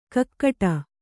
♪ kakkaṭa